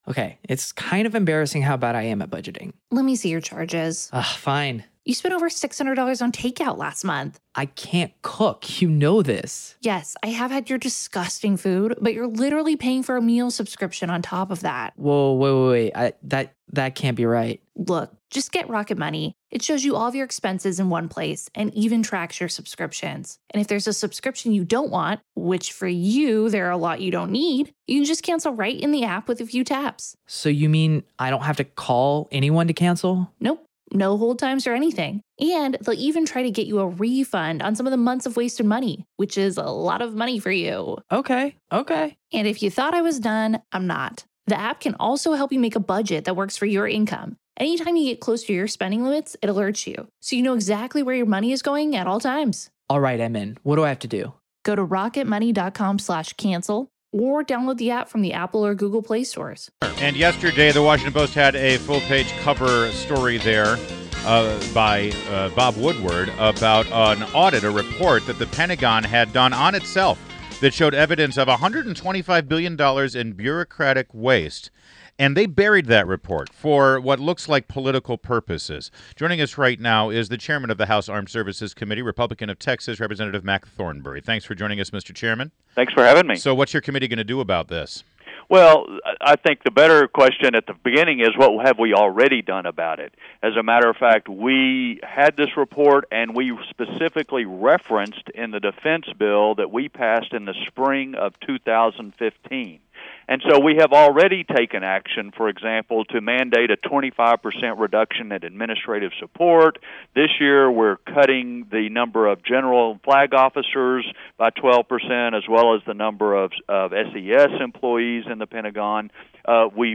INTERVIEW – REP. MAC THORNBERRY (R-TX) – House Armed Services Committee chairman